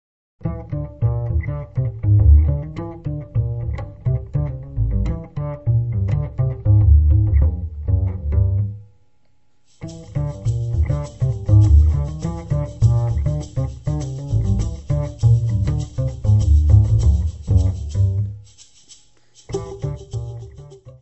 : stereo; 12 cm
Music Category/Genre:  Jazz / Blues